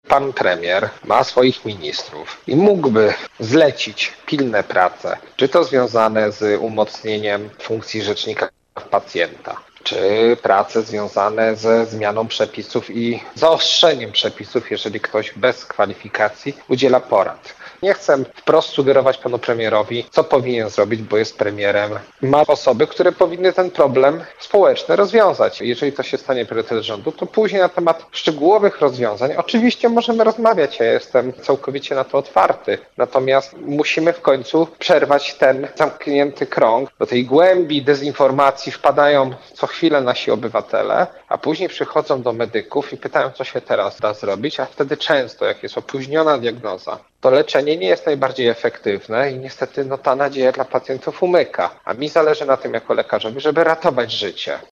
– dla Twojego radia mówi